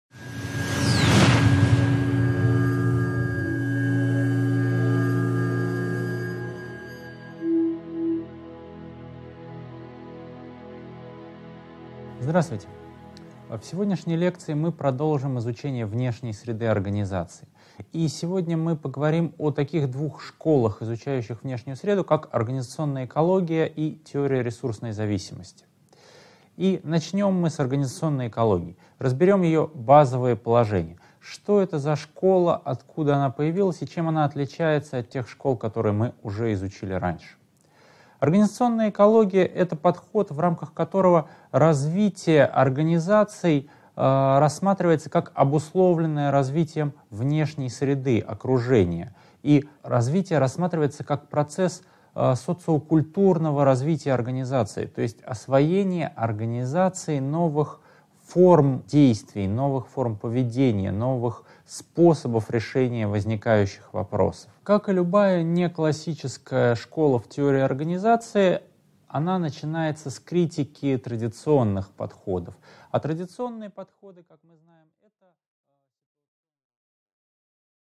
Аудиокнига 8.1. Организационная экология: базовые положения | Библиотека аудиокниг